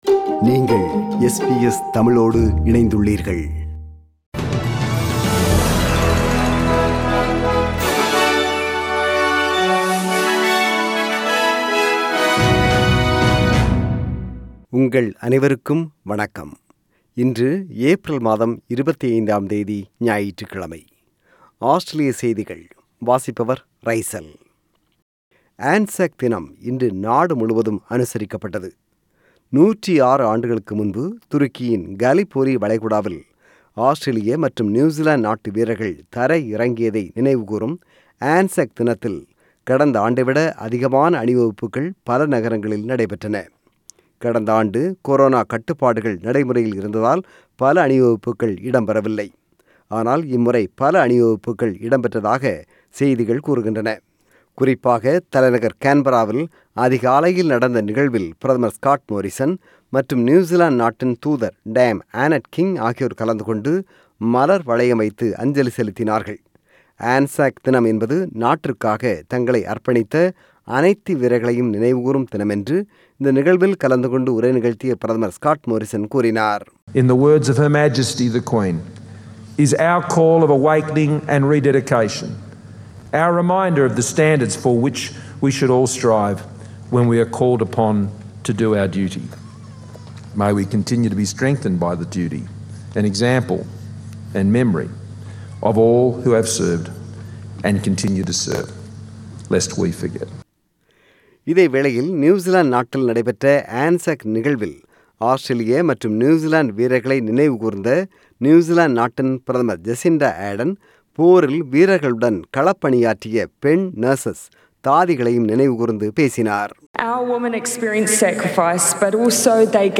Australian News: 25 April 2021 – Sunday